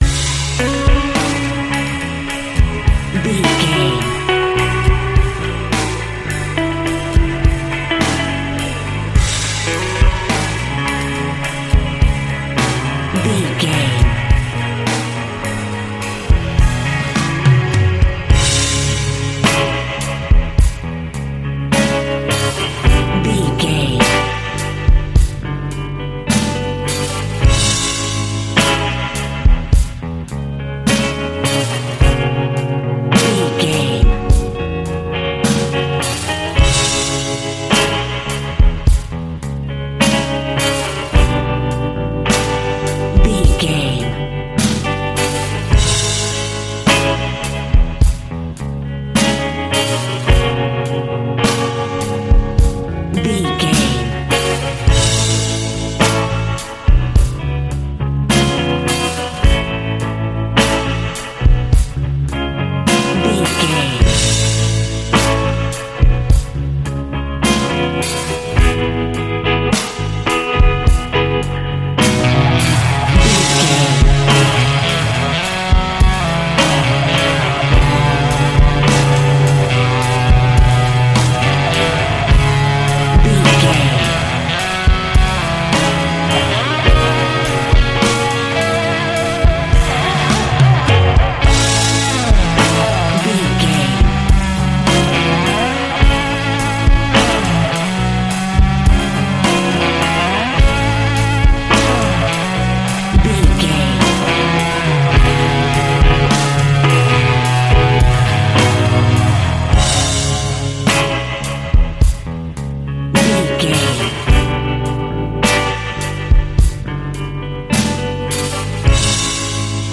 Aeolian/Minor
D♭
uplifting
bass guitar
electric guitar
drums
cheerful/happy